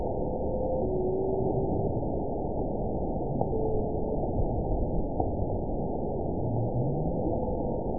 event 917605 date 04/10/23 time 04:17:11 GMT (2 years, 1 month ago) score 9.40 location TSS-AB01 detected by nrw target species NRW annotations +NRW Spectrogram: Frequency (kHz) vs. Time (s) audio not available .wav